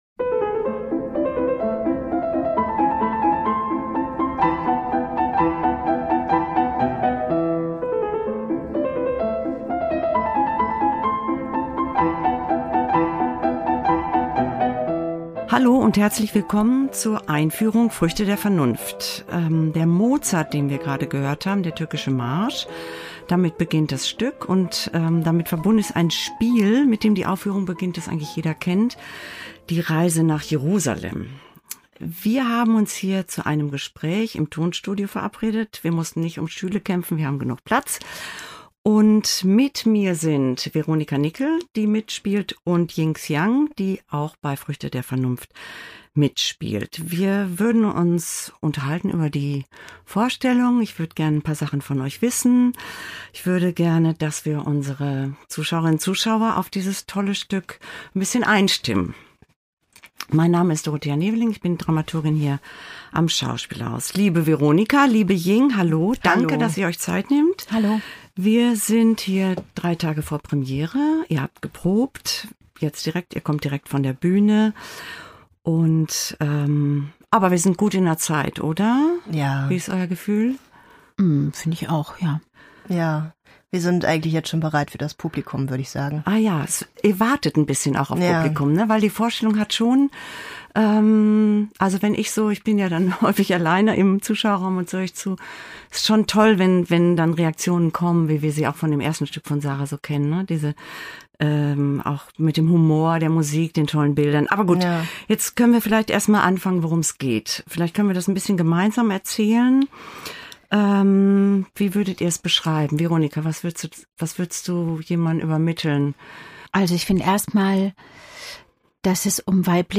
Erfahren Sie mehr über neue Inszenierungen aus dem Schauspielhaus Bochum in der neuen Episode der Talkreihe und Audioeinführung mit Künstler*innen und Dramaturg*innen der Produktion.